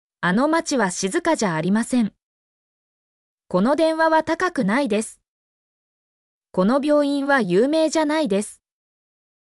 mp3-output-ttsfreedotcom-6_UcsNOQQw.mp3